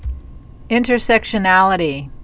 (ĭntər-sĕkshən-ălĭ-tē)